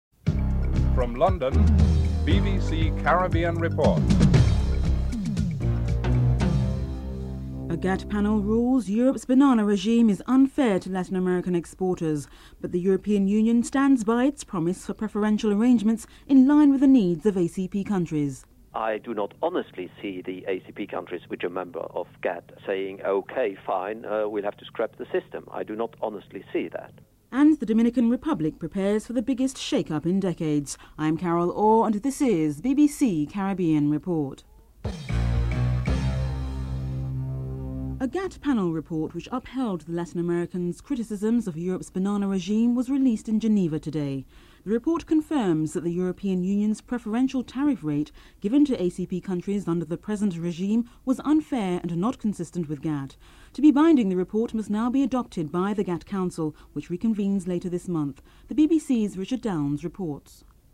Opposition leader Fred Mitchell speaks about how the average person in Bahamas feels about the refugee situation (10:17-13:11)
7. Wrap up and theme music (14:56-15:08)